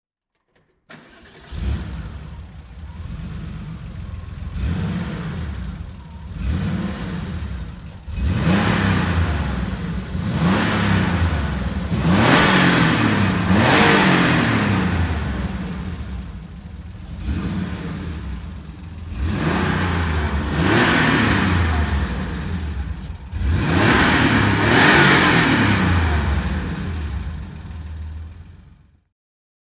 Porsche 964 C4 (1991) - Starten und Leerlauf